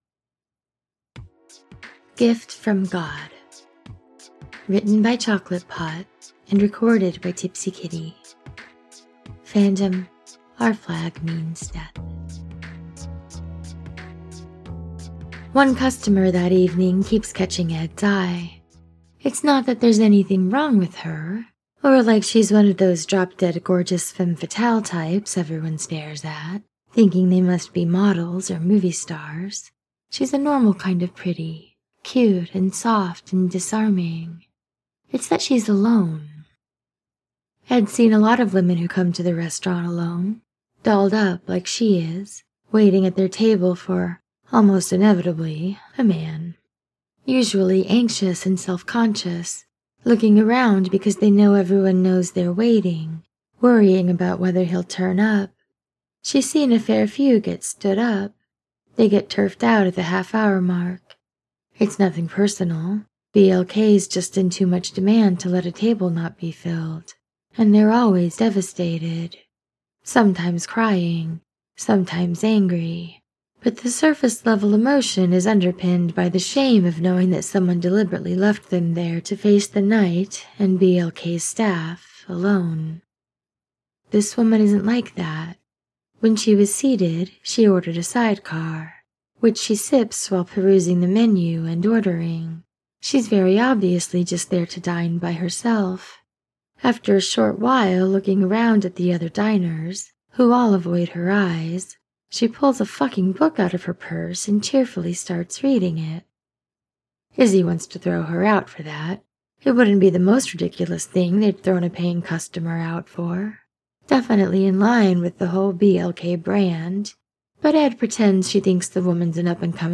with music: download mp3: here (r-click or press, and 'save link') [12 MB, 00:16:09]